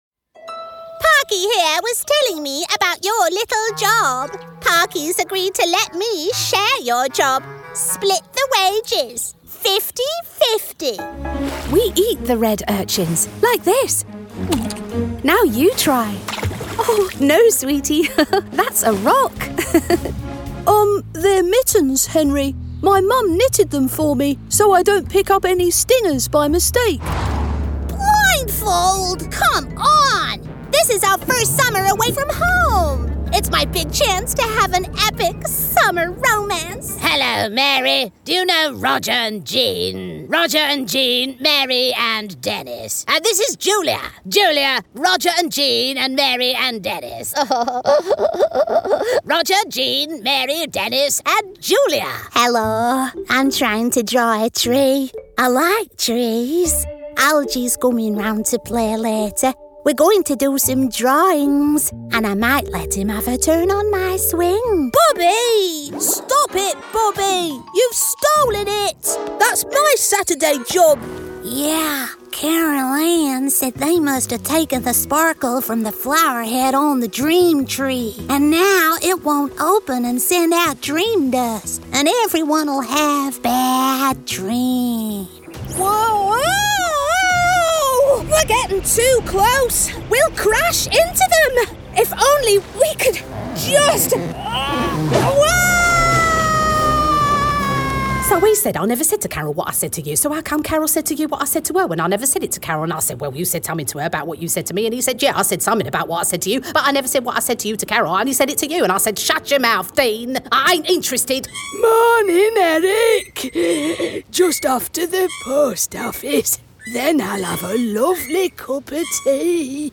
• Native Accent: RP
• Home Studio